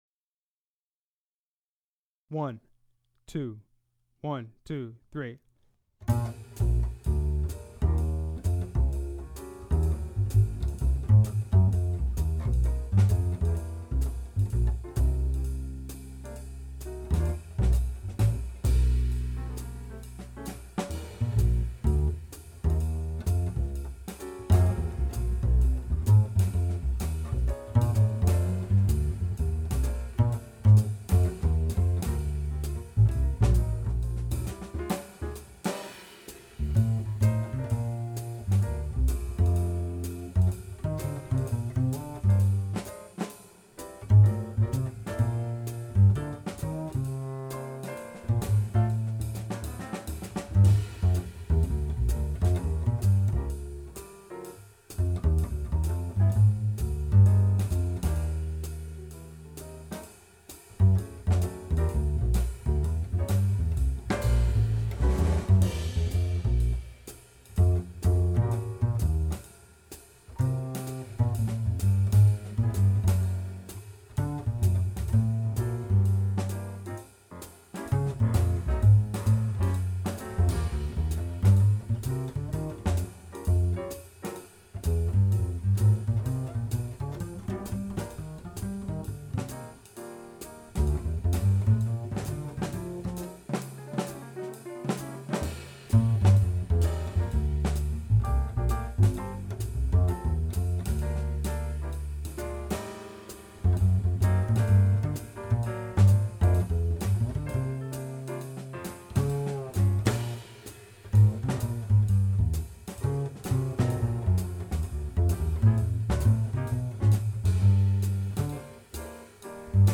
Bass Practice Track
bass_great_love__there_is_no_greater_love_.mp3